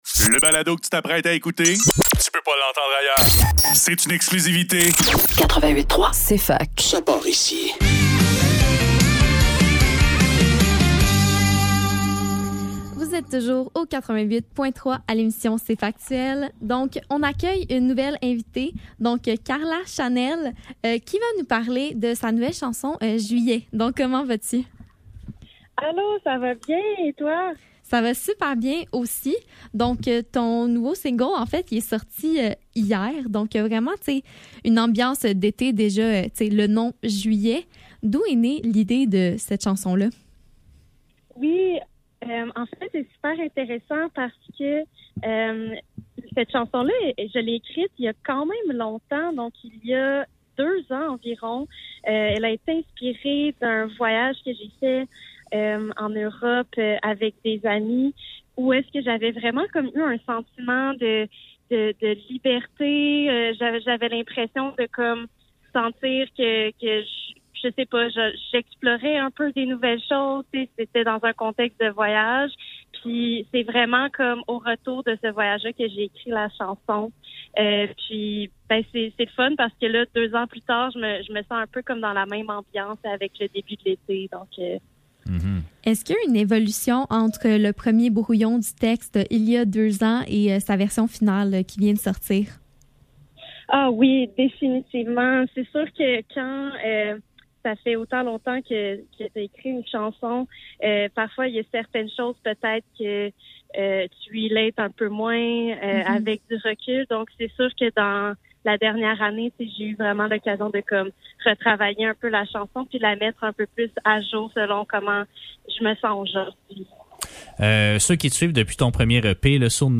Cfaktuel - Entrevue